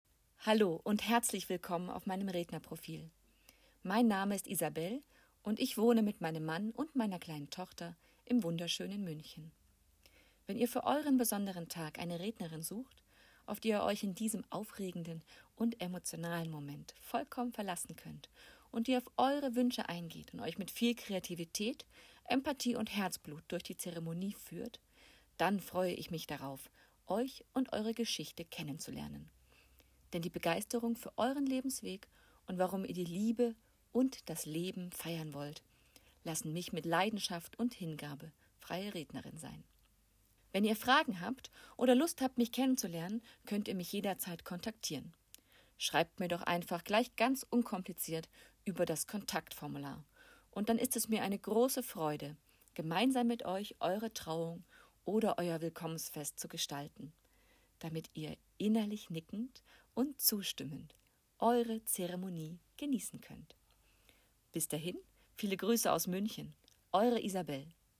Stimmprobe